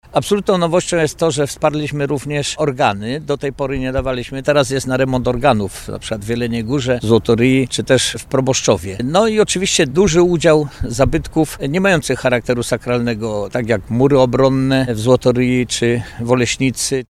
Tadeusz Samborski, członek zarządu województwa zaznacza, że zabytki, na które zostały przyznane dofinansowania mają zarówno charakter sakralny jak i świecki.